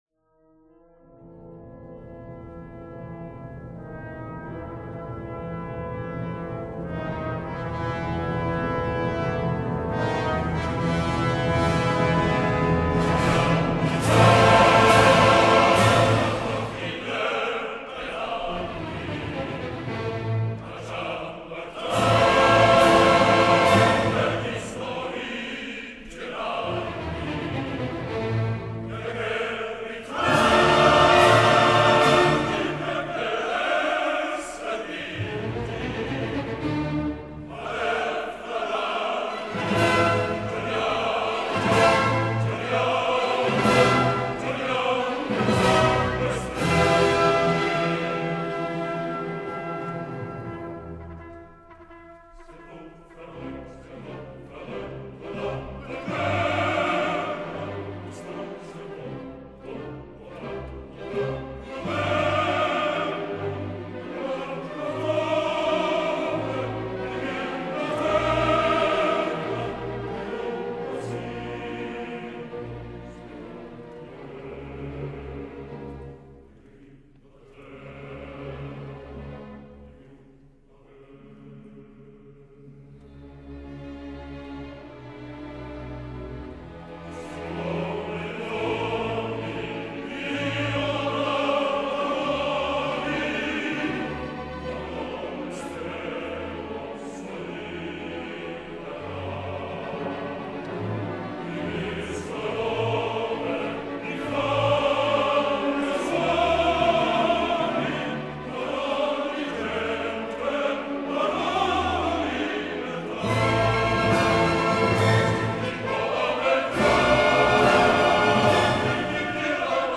MELODRAMMA